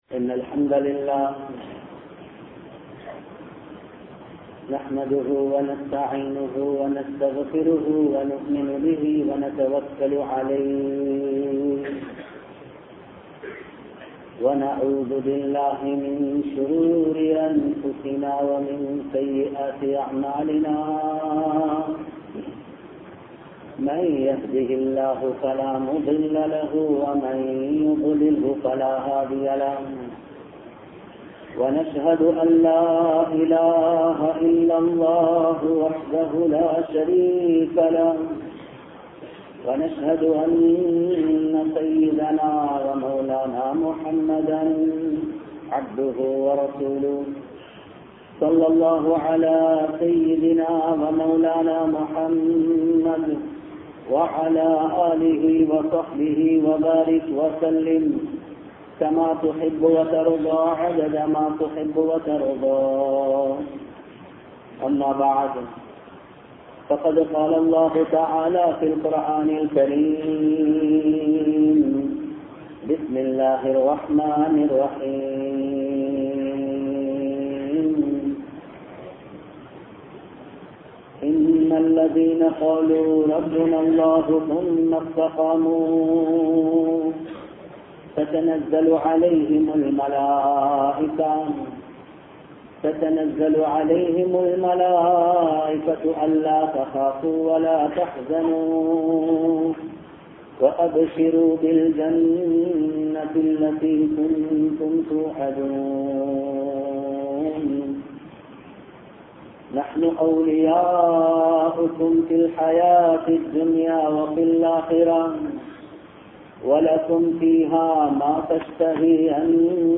Nabi(SAW)Avarhalin Vaalkai (நபி(ஸல்)அவர்களின் வாழ்க்கை) | Audio Bayans | All Ceylon Muslim Youth Community | Addalaichenai